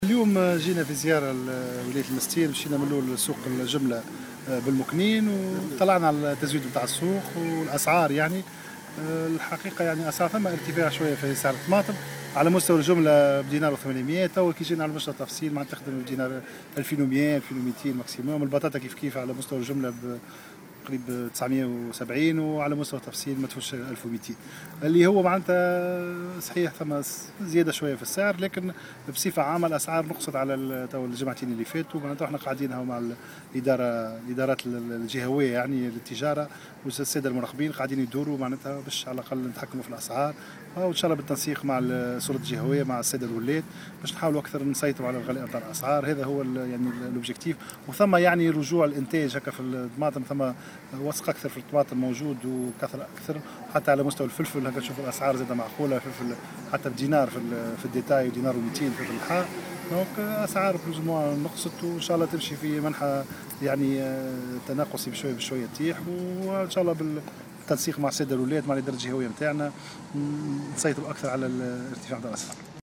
أكد وزير التجارة عمر الباهي في تصريح لمراسل "الجوهرة أف أم" على هامش زيارة أداها صباح اليوم إلى السوق المركزية بالمنستير، تسجيل انخفاض في أسعار بعض المنتوجات الفلاحية مقارنة بالأسابيع الماضية.